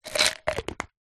Звуки аптечки
Выпили витамин для энергии